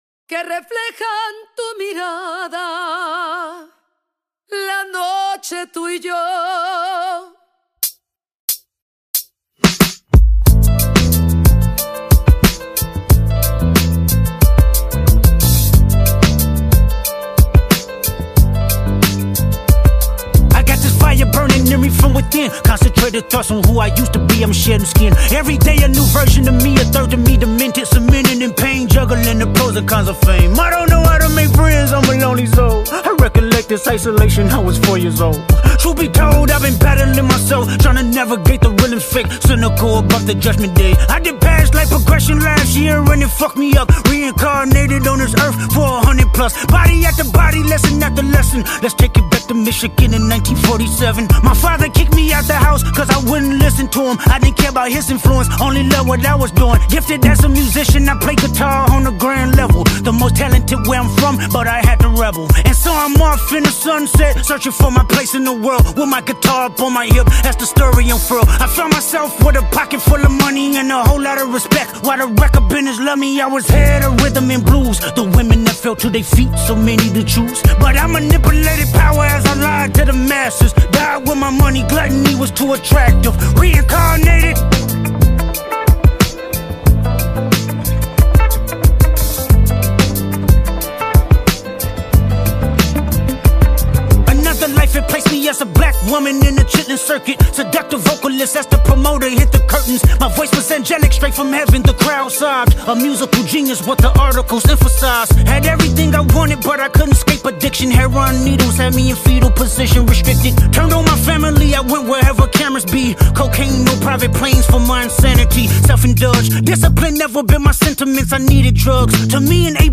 Hip Hop
Get this energizing song